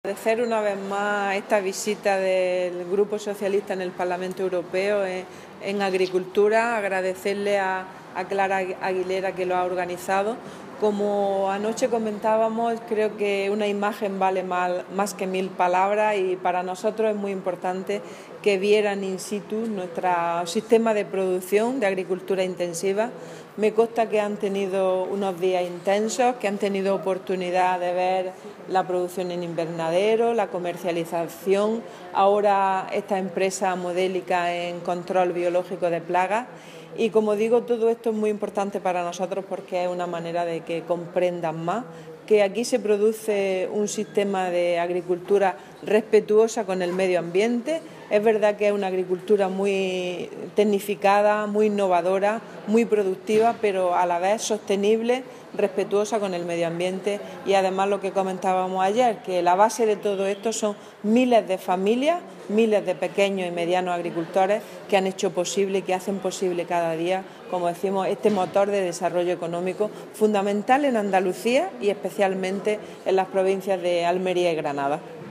Declaraciones de Carmen Ortiz sobre visita de europarlamentarios a Agrobío